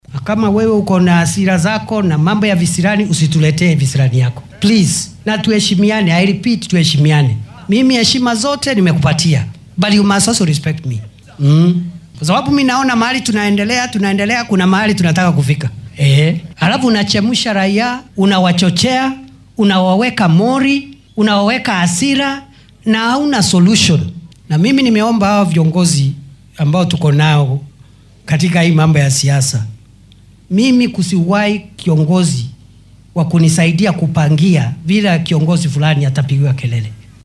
Waxaa uu madaxweyne ku xigeenkii hore ee waddanka Rigathi Gachagua ka dalbaday in uunan sameyn kicin dadweyne. Xill uu ku sugnaa deegaanka Waqooyiga Mbeere ee ismaamulka Embu ayuu Prof. Kithure Kindiki ku baaqay in laga wada shaqeeyo xoojinta midnimada iyo wadajirka. Waxaa uu Gachagua ka codsaday in dhaliilaha uu u jeedinaya dowladda ay noqdaan kuwo wax xallinaya.